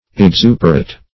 Exuperate \Ex*u"per*ate\